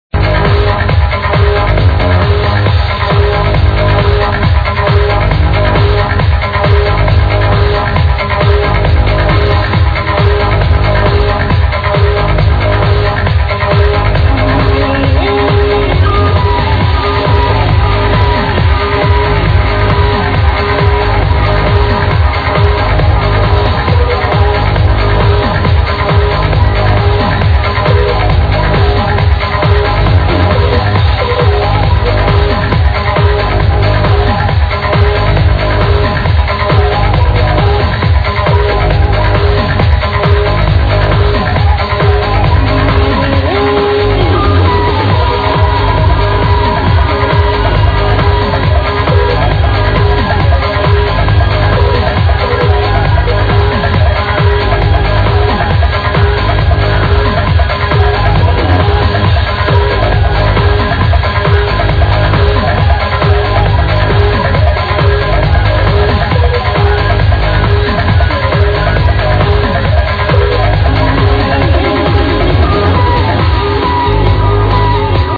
sounds like a dub